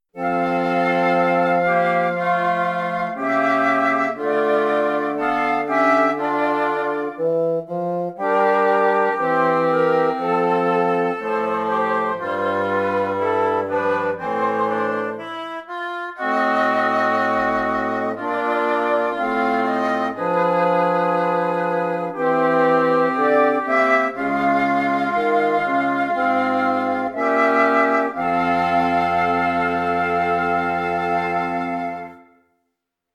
Música clàssica i contemporània
A2-dictat-harmonic-classica-cobla-audio-24-06.mp3